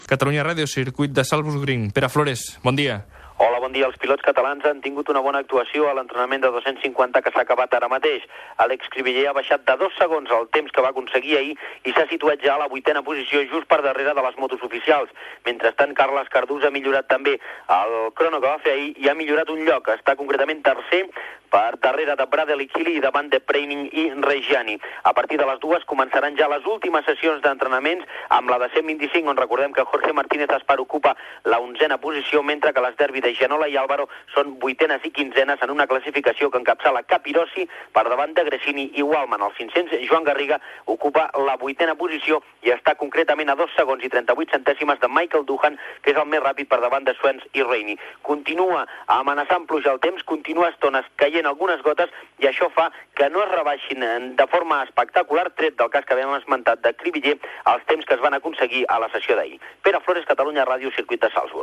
Connexió amb els entrenaments lliures del Gran Premi d'Àustria de Motociclisme al circuit de Salzburgring
Esportiu